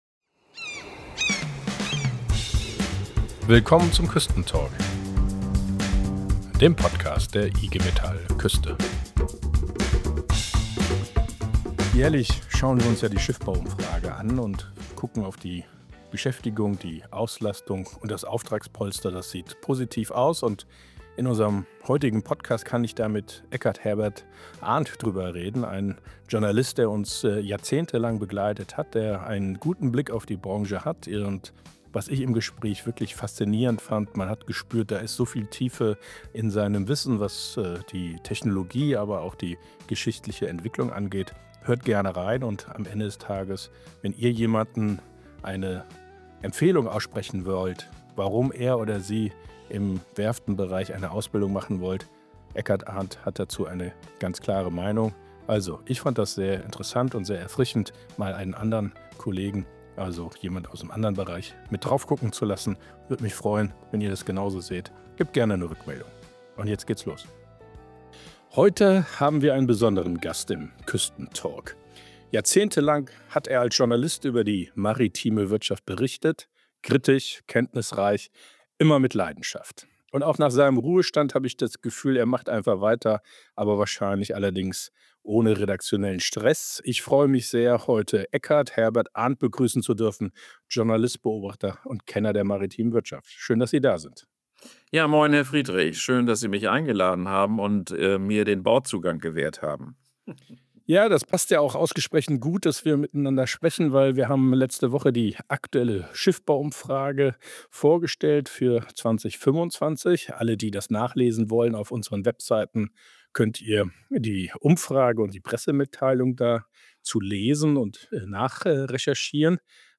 Ein Gespräch voller Tiefe, Leidenschaft und Perspektiven.